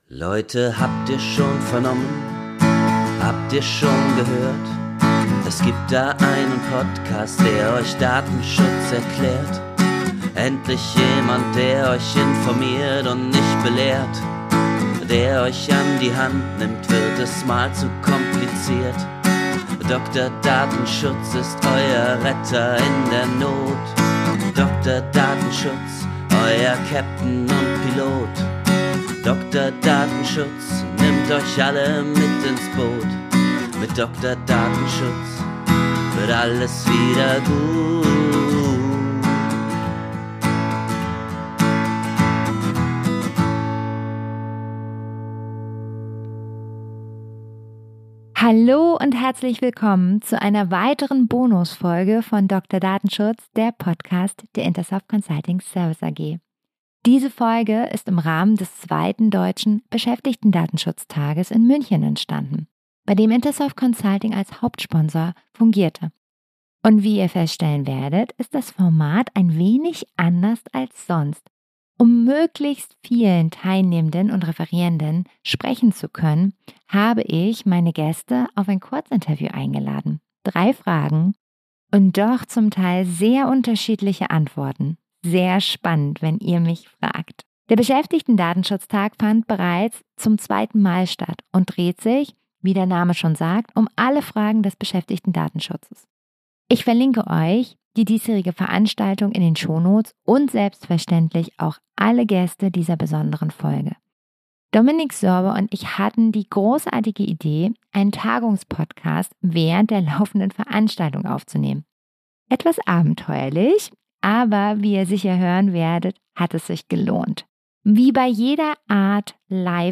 Neben Behördenleitern, Beratern und Beraterinnen aus der Branche und internen Datenschützern stellt sich heraus, dass besonders KI-Anwendungen Unternehmen vor Herausforderungen stellen. Exotischere Themen haben genauso Raum in den Kurzinterviews wie Klassiker.